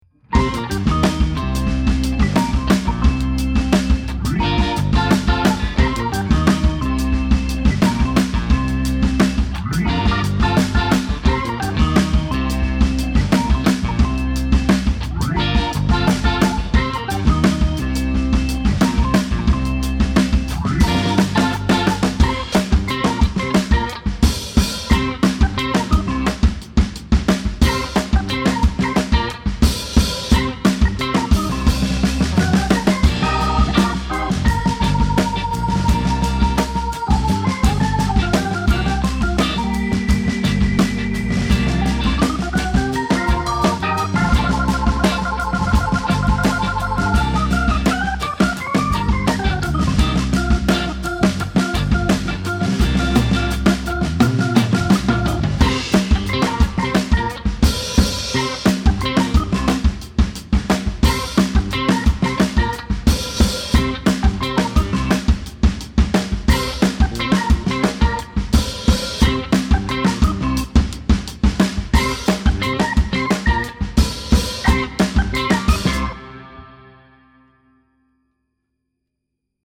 Vi spelar instrumental cool funk i 60/70-tals stil.
Kvartett; elgitarr, elbas, hammond orgel och livetrummor.